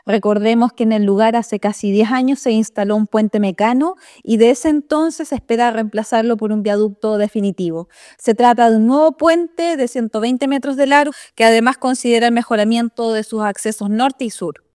La seremi de Obras Públicas, Nuvia Peralta, entregó detalles respecto a la longitud del puente, el cual medirá cerca de 120 metros de largo.